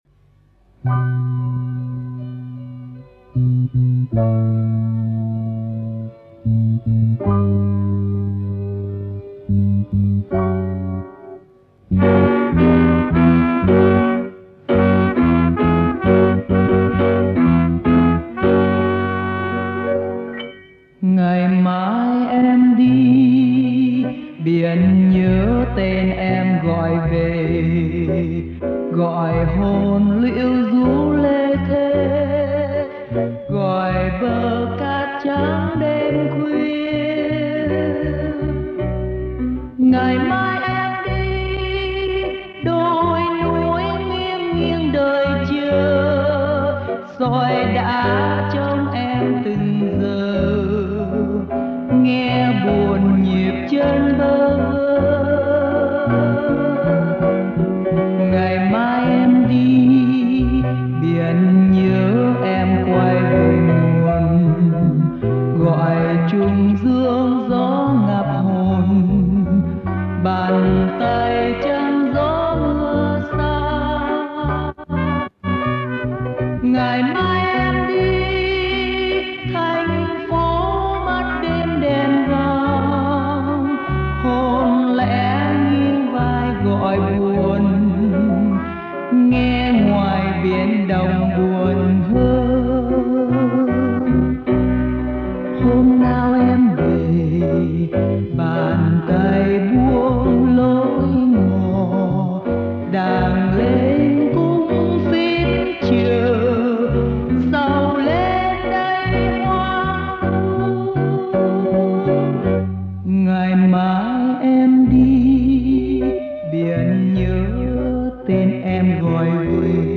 giai điệu giản dị